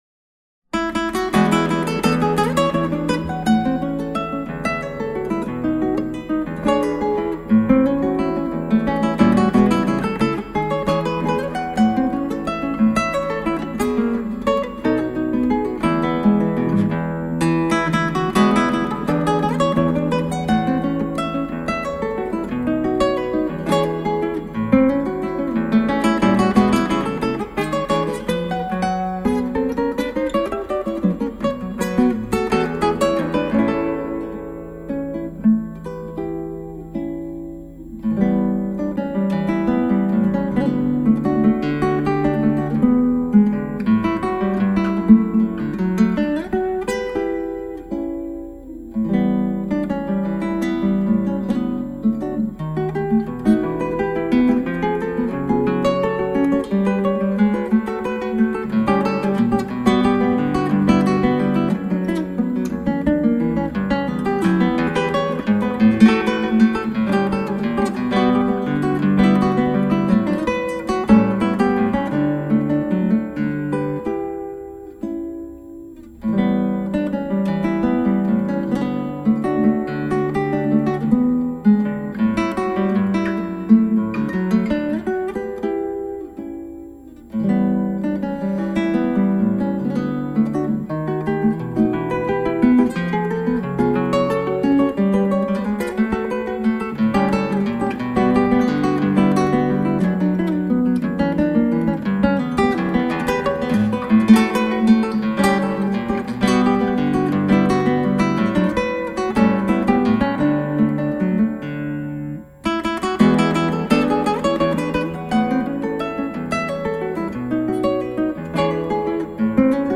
クラシックギター　ストリーミング　コンサート
これもラウロのベネズエラワルツの曲なのですが、例によってあまりワルツっぽくないです。